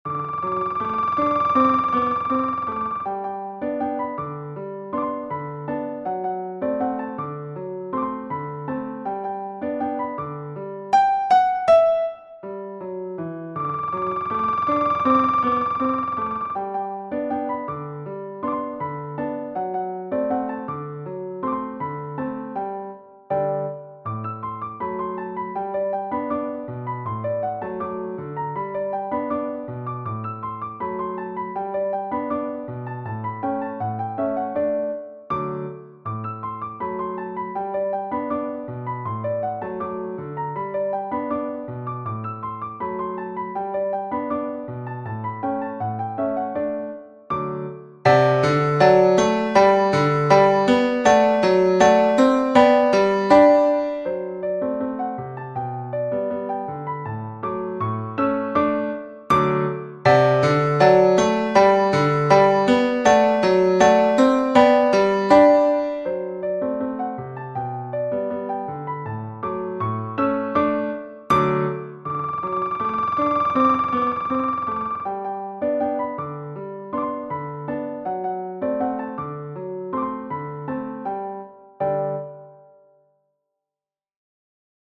Greco, V. Genere: Ballabili "Rodriguez Pena" è un tango composto nel 1911 dal direttore d'orchestra e bandoneonista argentino Vicente Greco su testo di Juan Velich.